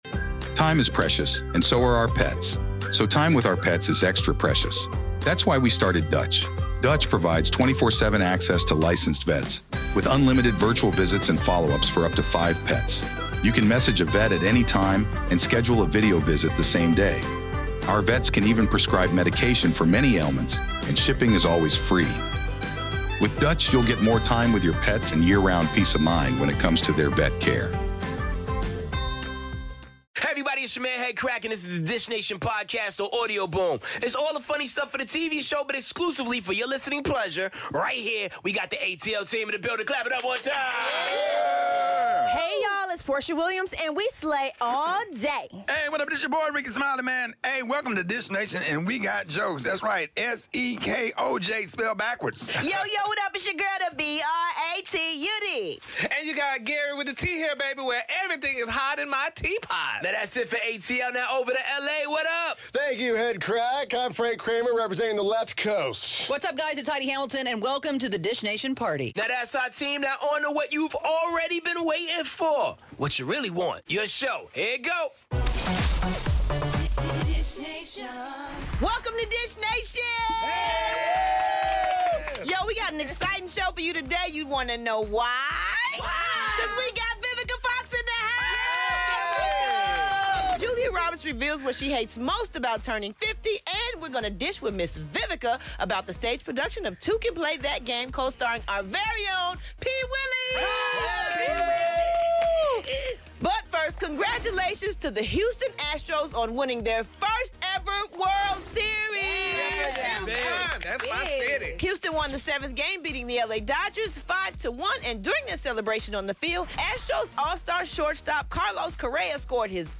Vivica A. Fox is in studio dishing with us and we look at the latest in the Tyrese Vs. The Rock feud. Plus the latest on: Chris Hemsworth, Jennifer Aniston, Sandra Bullock, Kelly Clarkson, Alex Trebek, Channing Tatum, and much more!